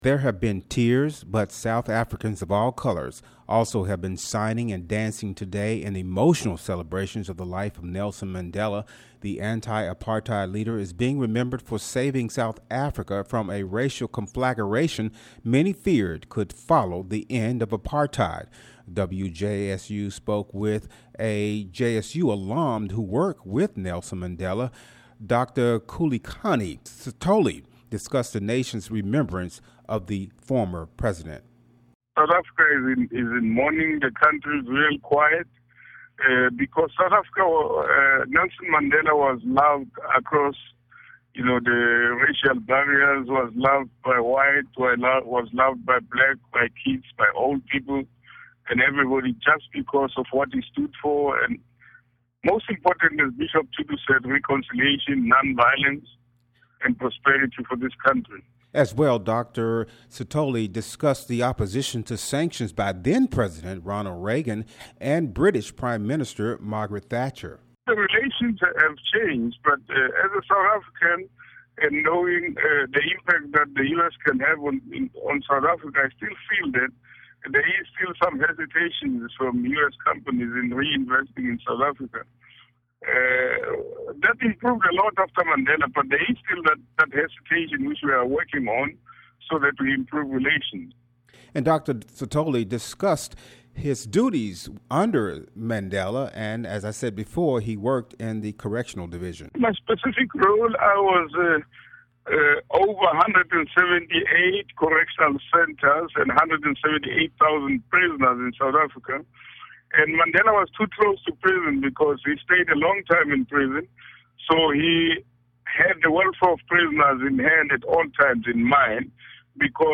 Interview: WJSU speaks with JSU alum who worked with Nelson Mandela